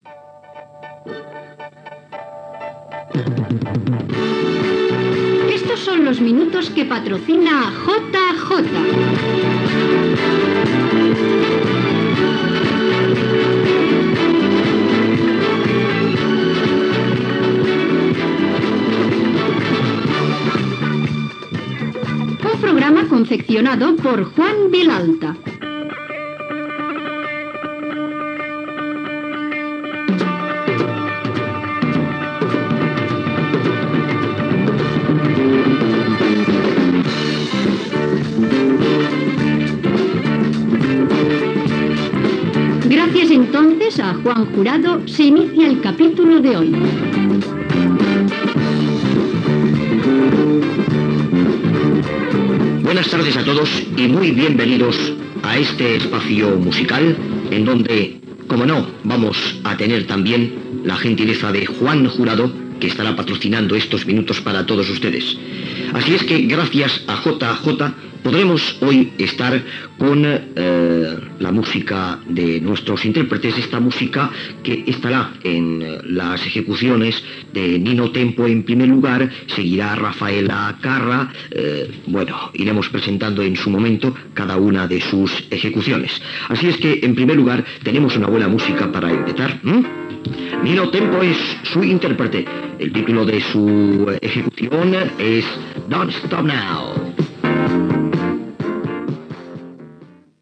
Publicitat i tema musical